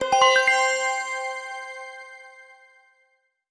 Jewel_hit_8.wav